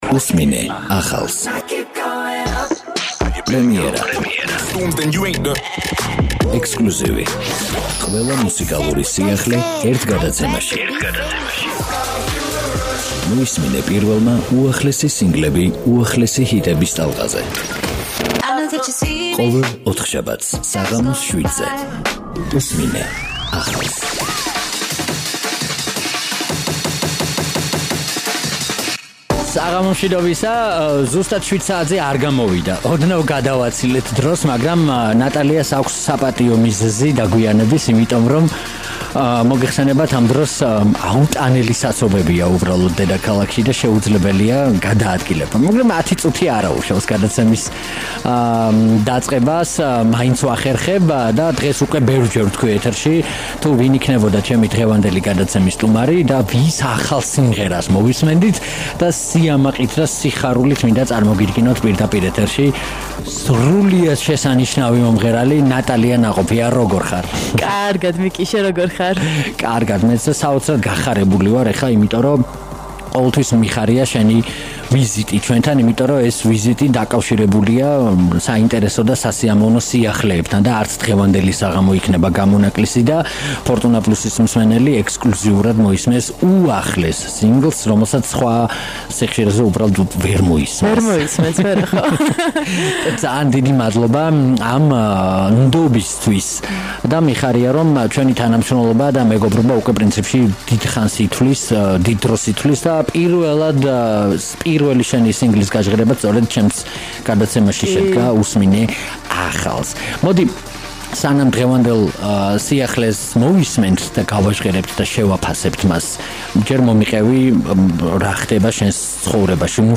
რადიო „ფორტუნა პლუსის“ გადაცემა „უსმინე ახალს“ სტუმარია მომღერალი